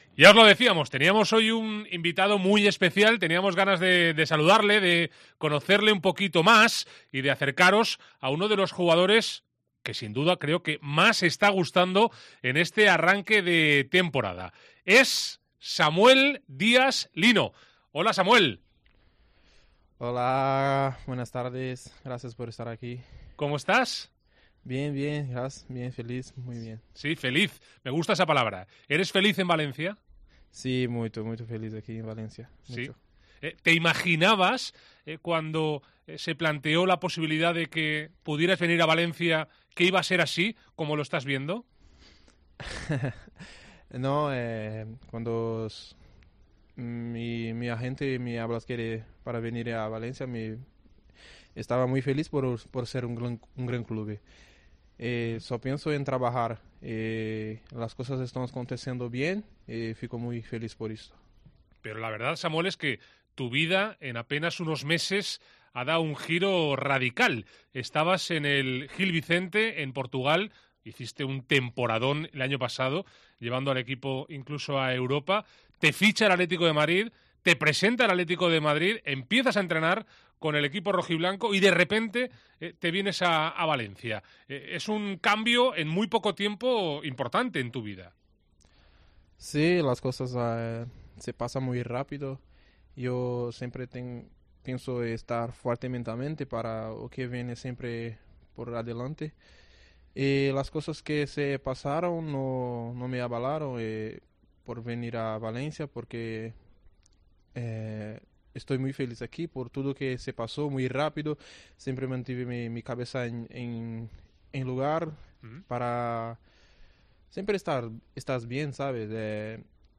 Entrevista de Samuel Lino en COPE